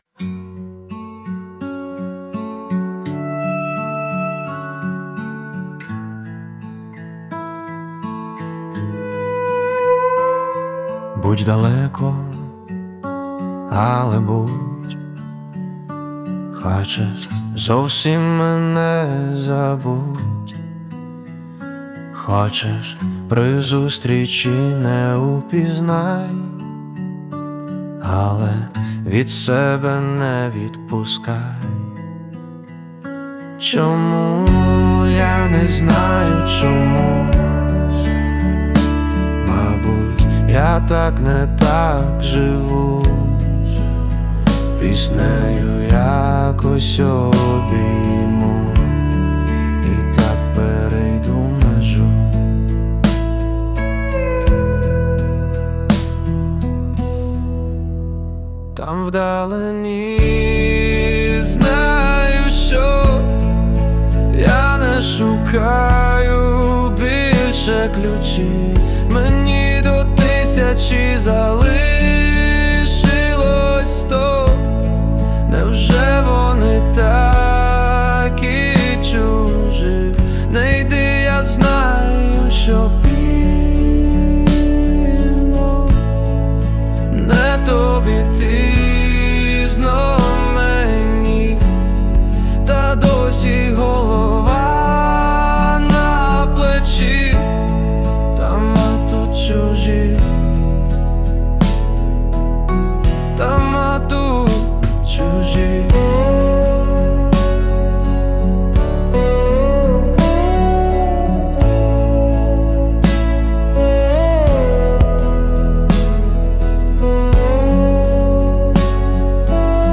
Цікаве виконання від SUNO.
СТИЛЬОВІ ЖАНРИ: Ліричний
Дуже зворушлива пісня! 16 Дуже гарно! 16 Дуже сподобалась! 16